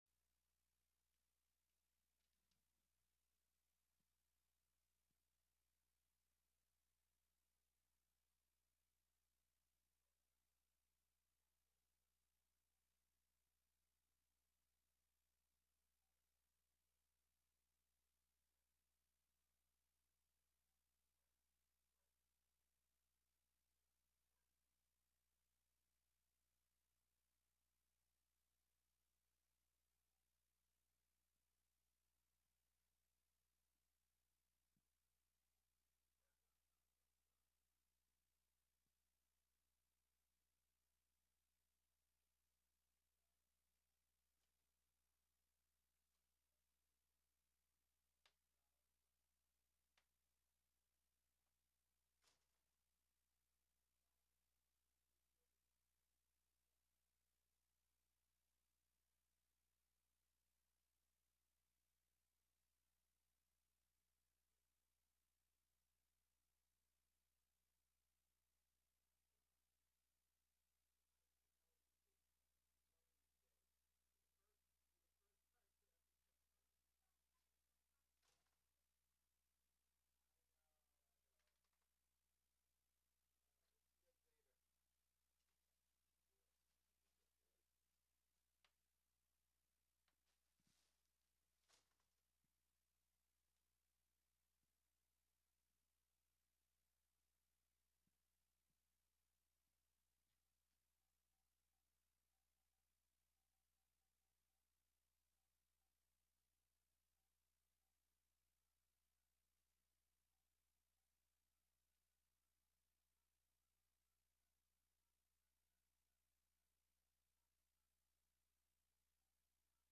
broadcasts live with music, call-ins, news, announcements, and interviews